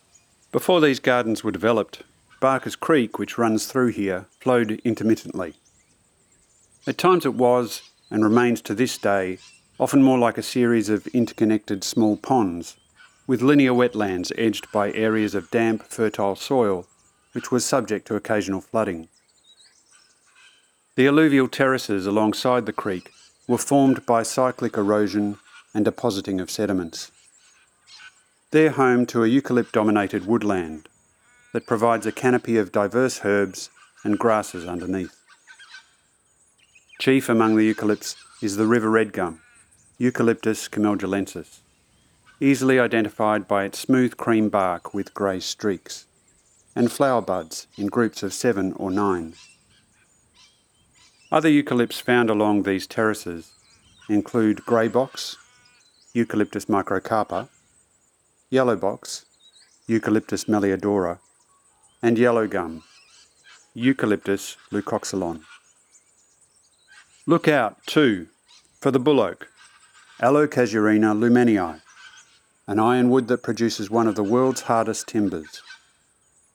Audio tour
cbg-audio-guide-alluvial-terrace.wav